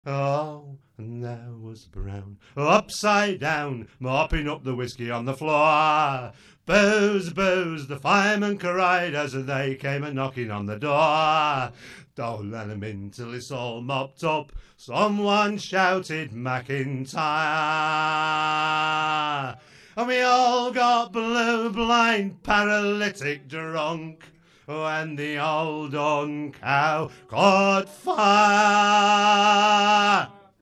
He has an excellent voice, claiming that he is not a guitarist and the guitar is merely there as background support. His intricate playing, in a variety of styles, suggests otherwise.